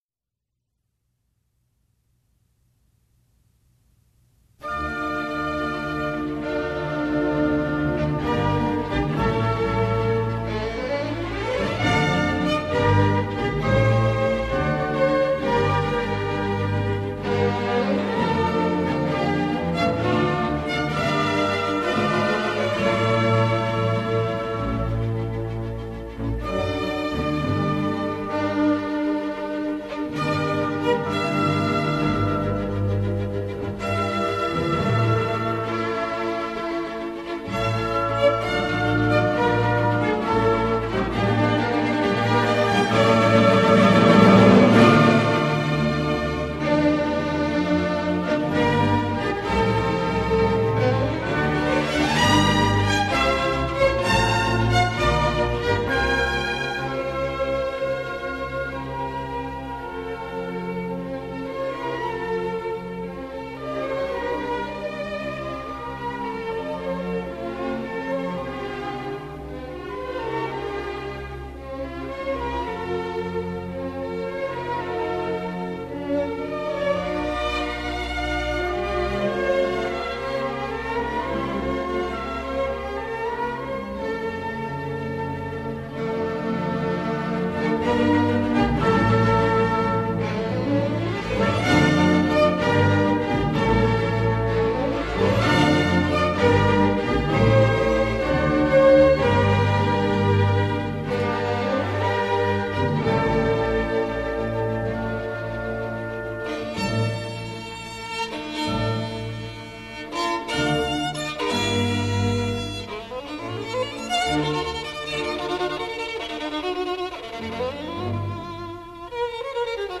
小提琴独奏曲、协奏曲
他的演奏优雅富有诗意，又兼具 对深度的表达。
他的录音质量极佳，没有特别的癖性，获得大多数听众喜爱。